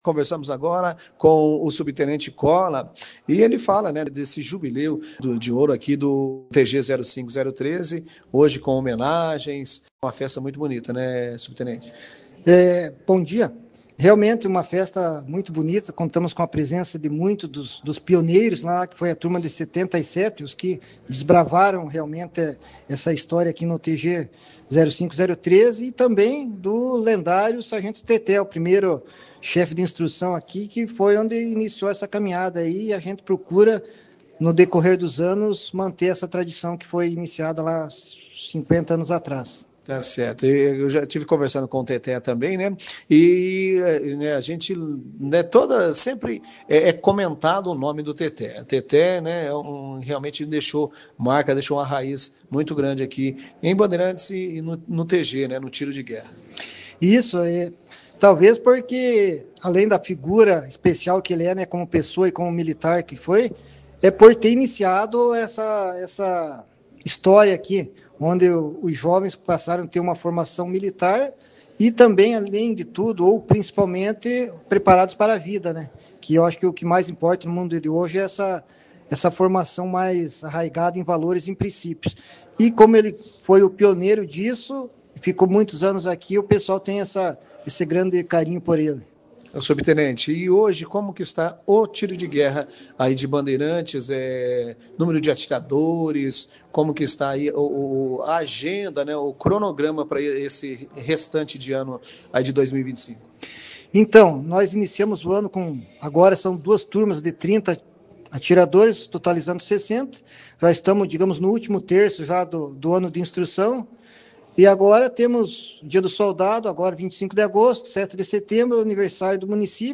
A celebração foi destaque da 2ª edição do jornal Operação Cidade